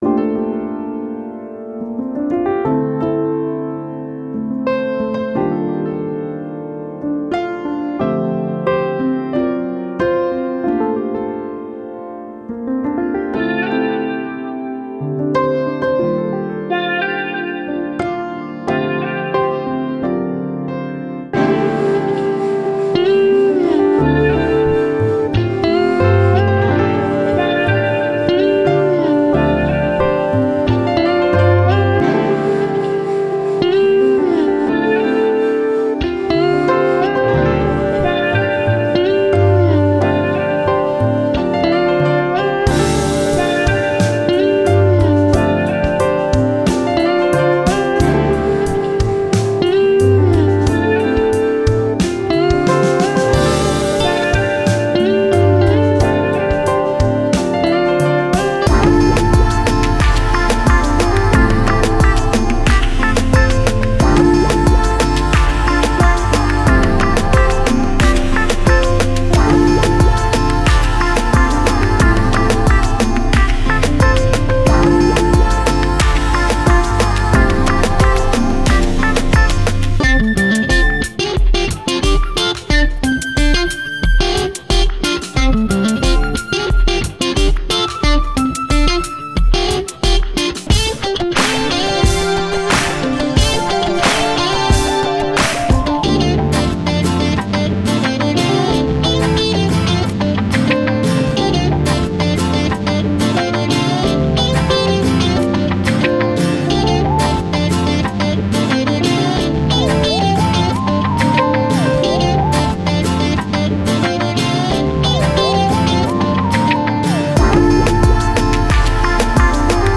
A restful jazz world composition.
Categories: 2021jazz